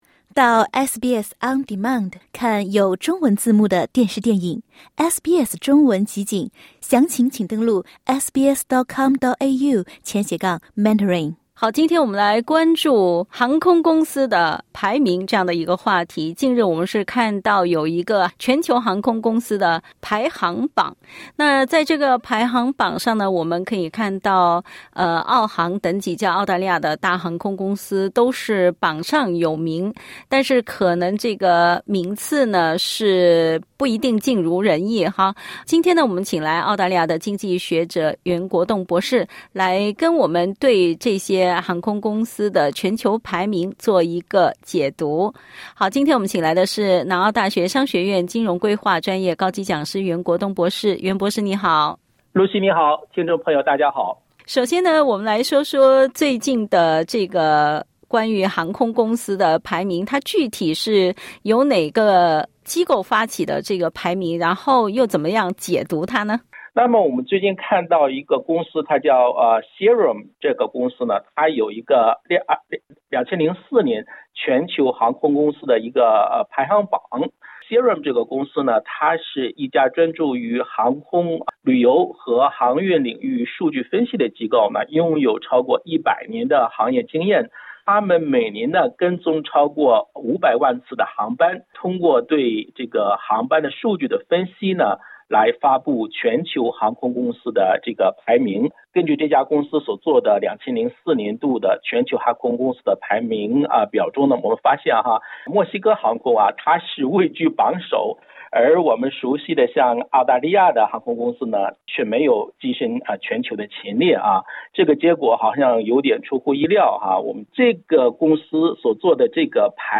（点击音频收听详细采访） 最近几个月是大家出行度假的高峰期，因此买机票就成为一个热门话题。